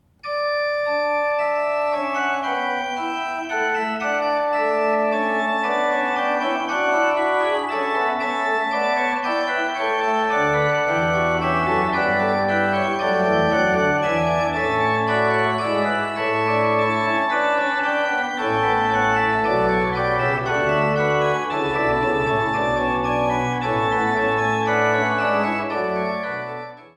Bourdon 16'
Montre 8'
Soubasse 16'